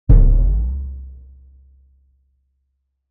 【効果音】コミカルなバウンド
-エフェクト
大きな物が弾んでバウンドするようなコミカルな効果音素材です。...